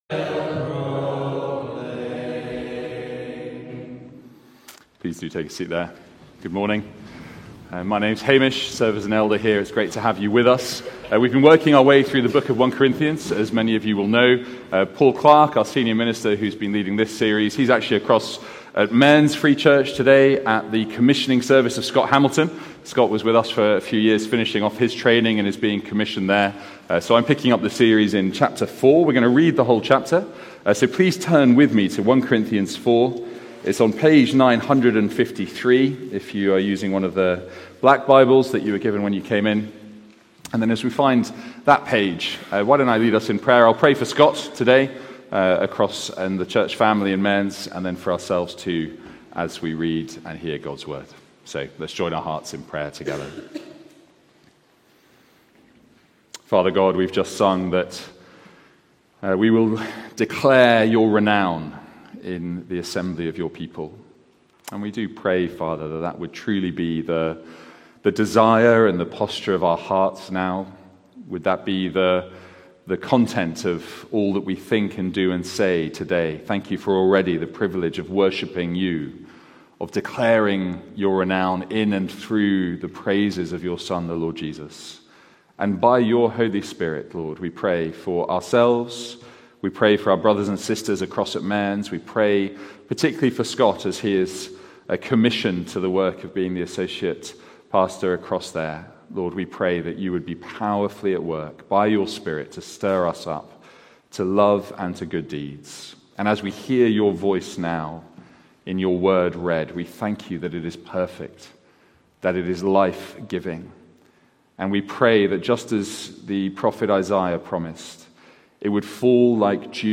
Sermons | St Andrews Free Church